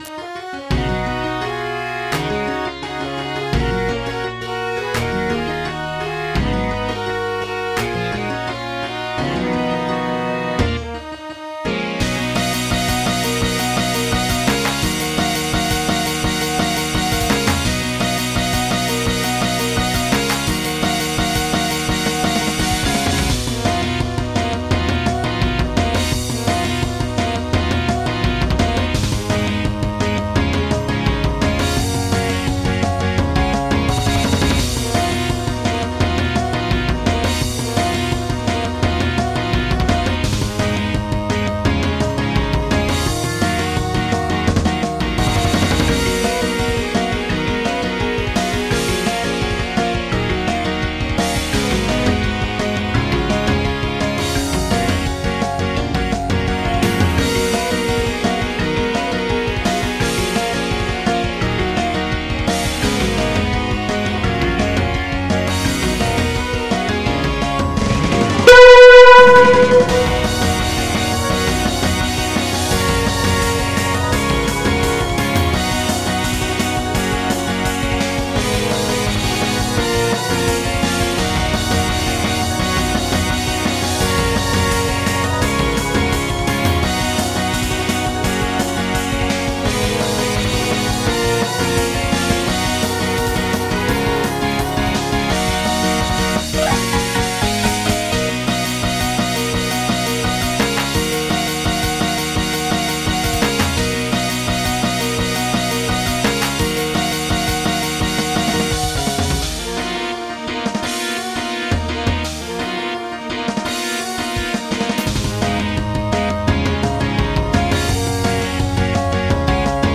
MIDI 242.21 KB MP3 (Converted) 4.29 MB MIDI-XML Sheet Music
is a J-pop, J-rock, pop punk, pop rock, rock song